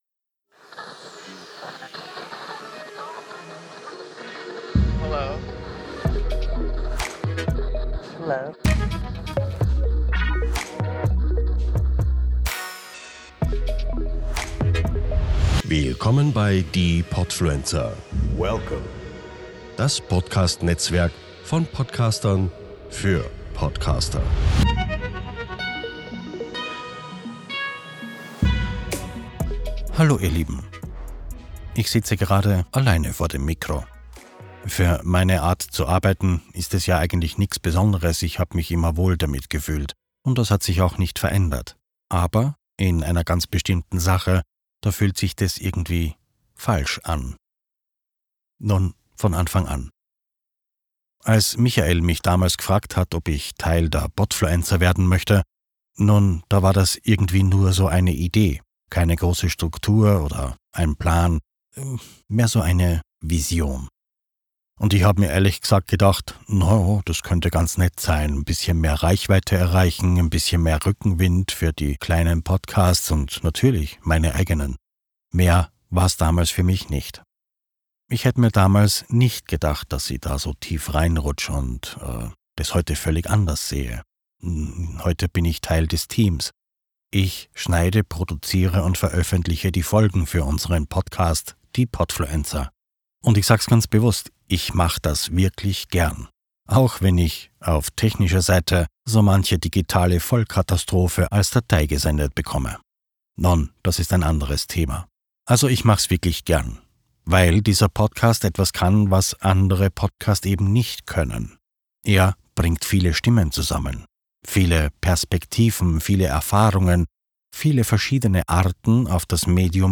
Nachdenklich und ein wenig mahnend führt er uns vor Augen, worauf
Music by Nikitsan Music